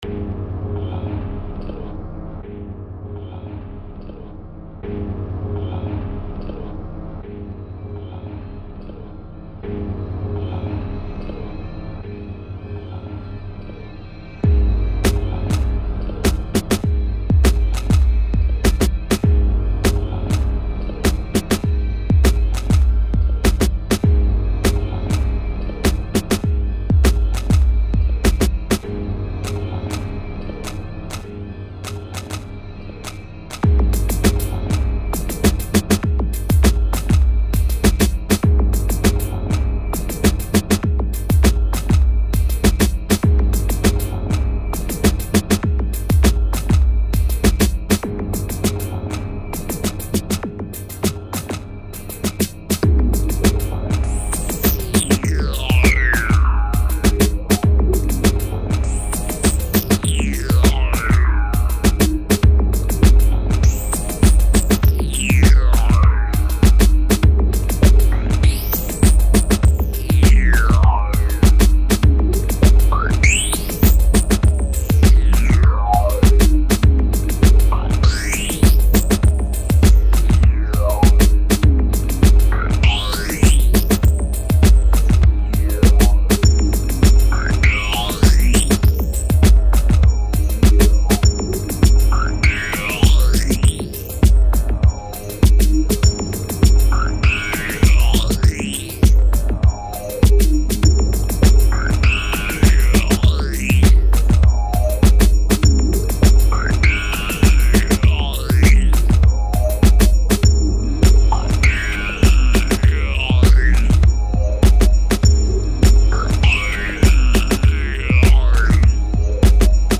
dark & electronic, all by me